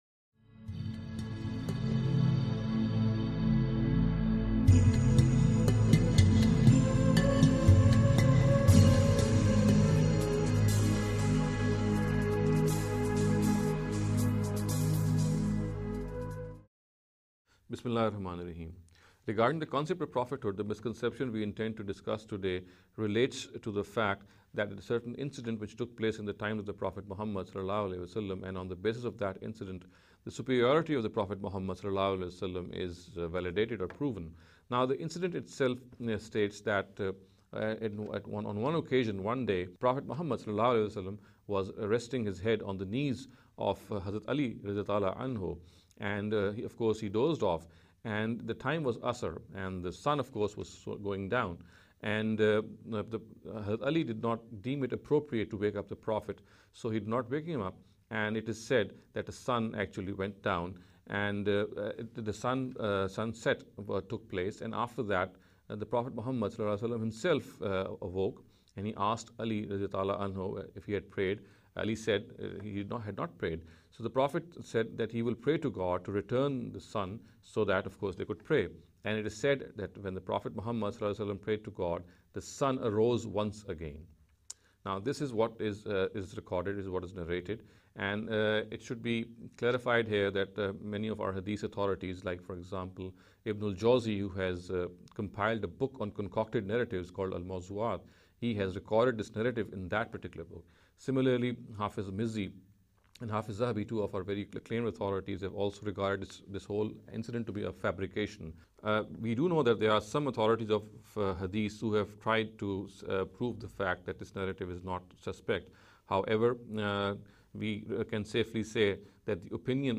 This lecture series will deal with some misconception regarding the Concept of Prophethood.